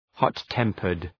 Προφορά
{,hɒt’tempərd}